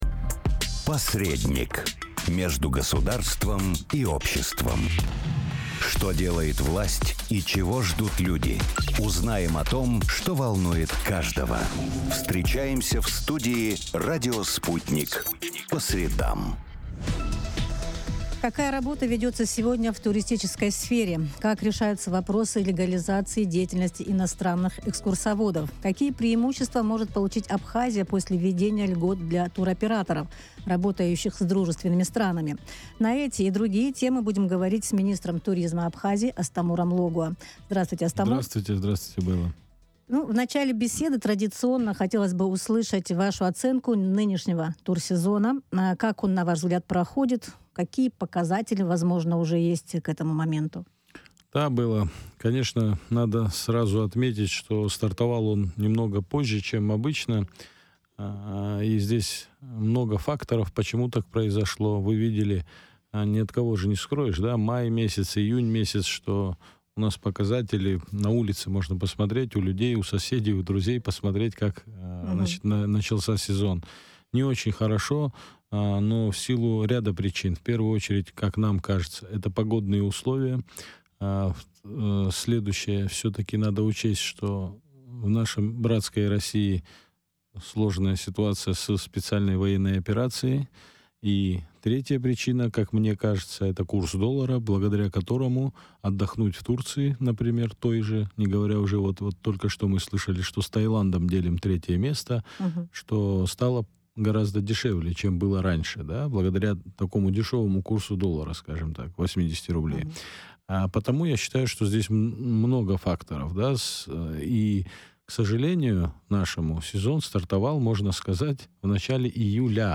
Жаркая пора для турсферы. Успехи и проблемы сезона в интервью с министром туризма Абхазии